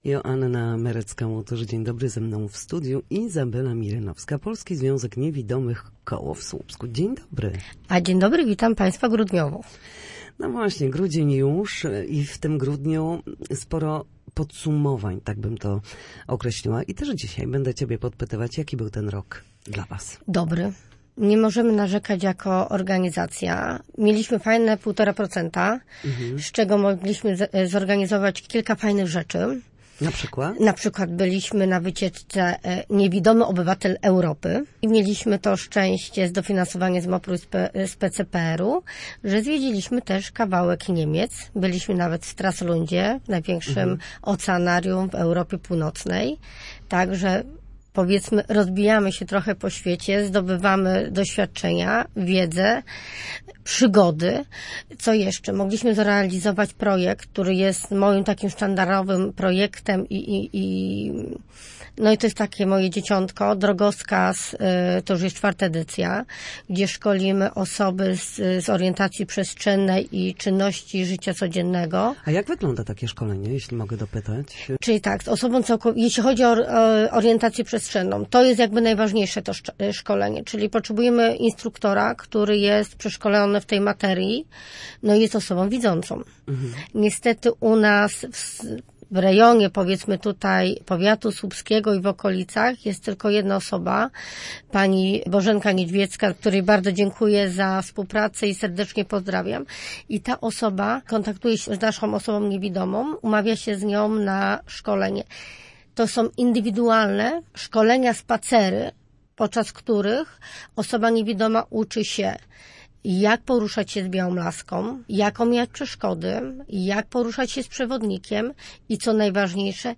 Na naszej antenie podsumowała miniony rok i opowiedziała o tym, jak ważne jest korzystanie z białej laski przy poruszaniu się po ulicach oraz dlaczego tak niewiele osób korzysta ze wsparcia psów